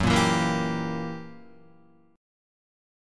F#7b9 chord